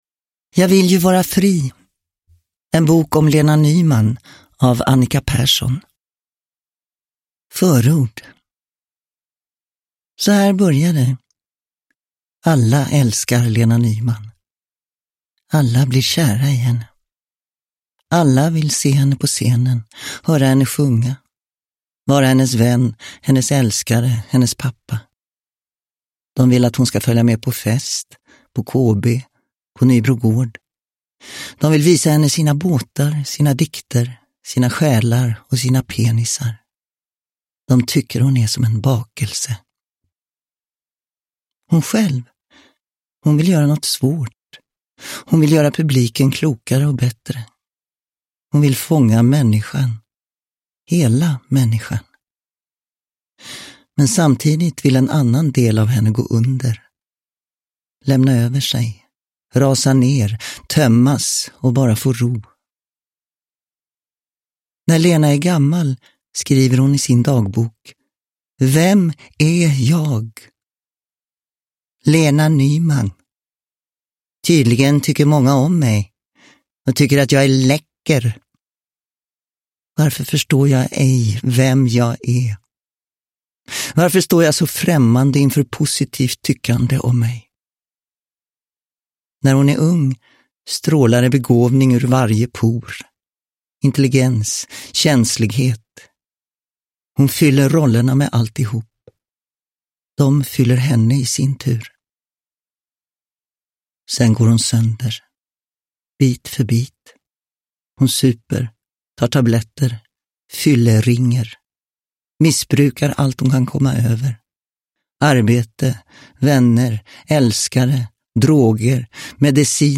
Jag vill ju vara fri : en bok om Lena Nyman – Ljudbok – Laddas ner